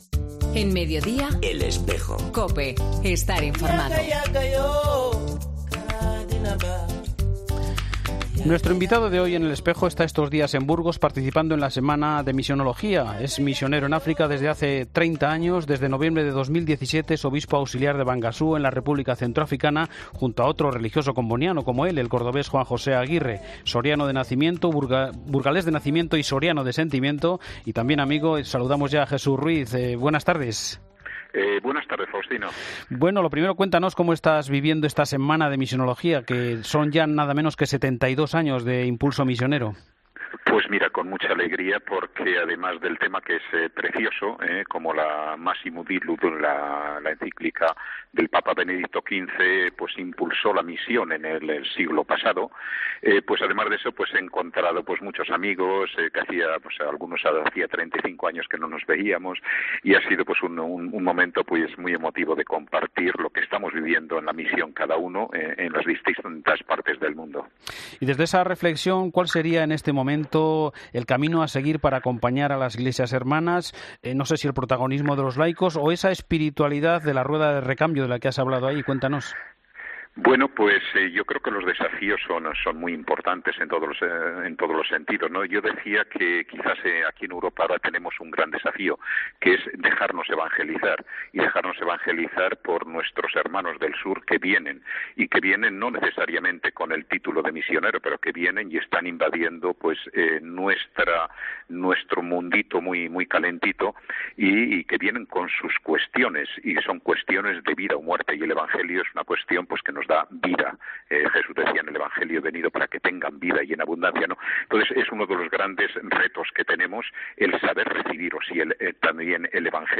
Hablamos con el obispo auxiliar de la Diócesis de Bangassou, que se encuentra en nuestro país participando en Burgos, en la Semana de Misionología.